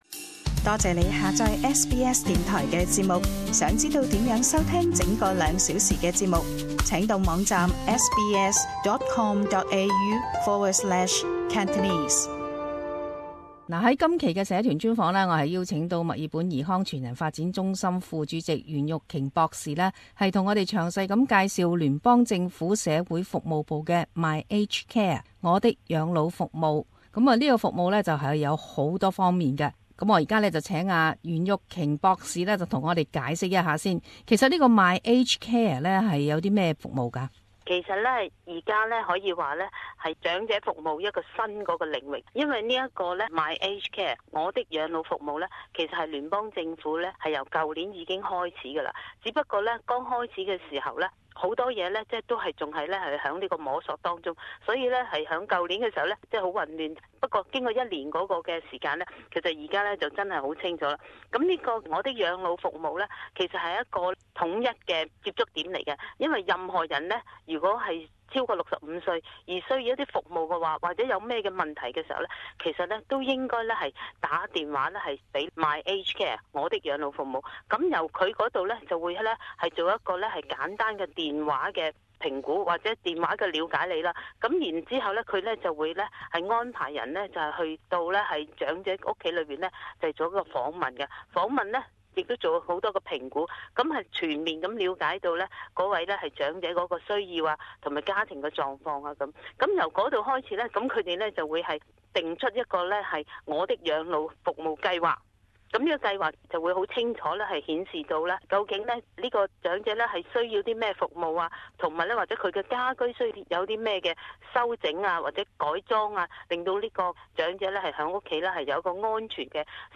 【社团专访】我的养老服务计划迈向新领域